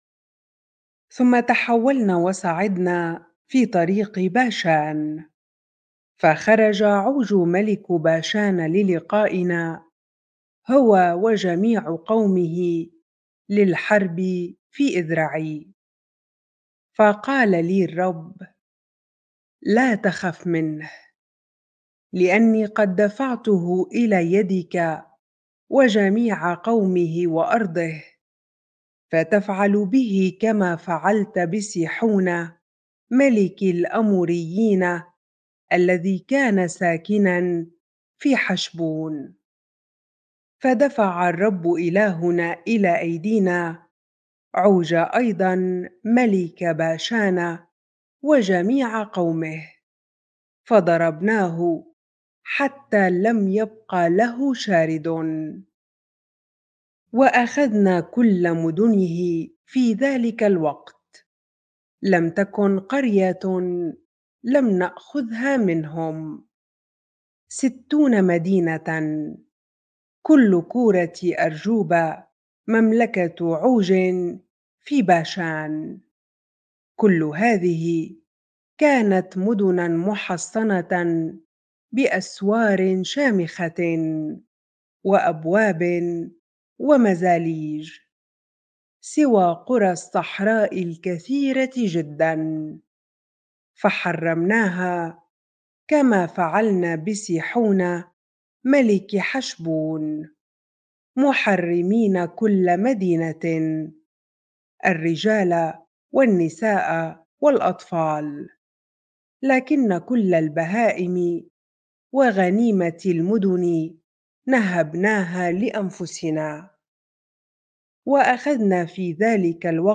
bible-reading-deuteronomy 3 ar